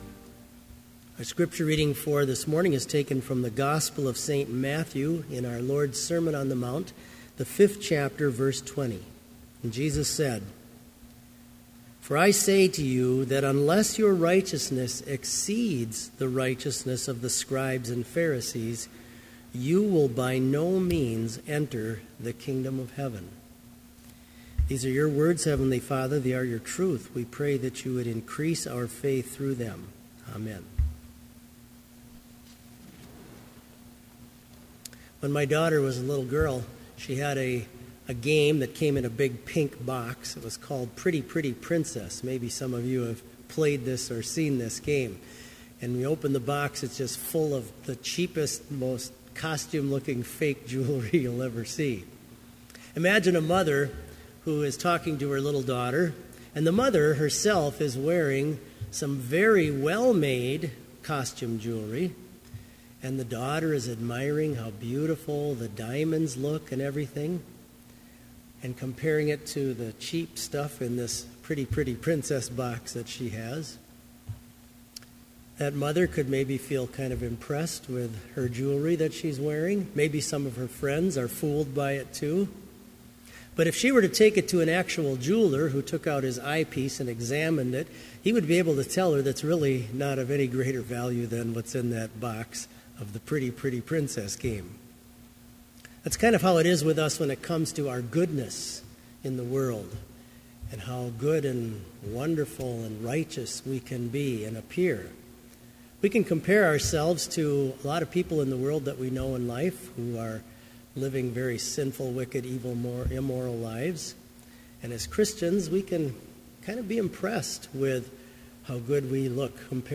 Complete service audio for Summer Chapel - August 6, 2014